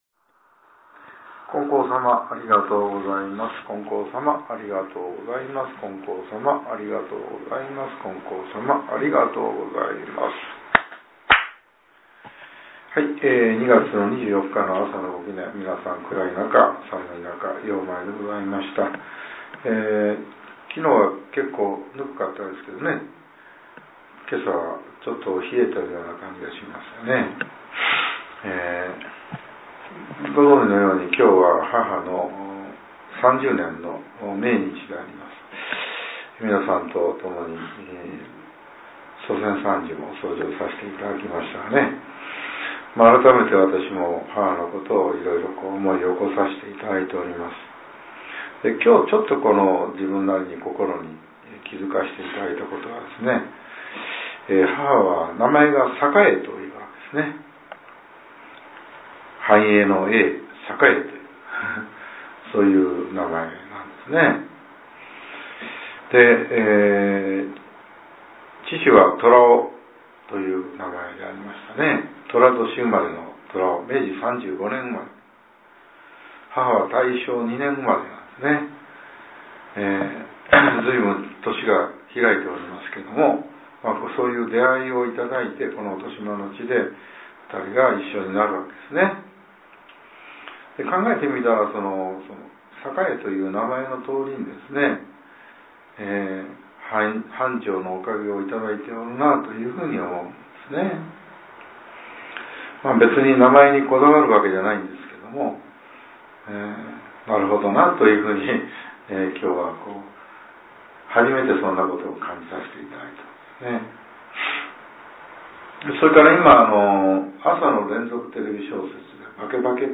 令和８年２月２４日（朝）のお話が、音声ブログとして更新させれています。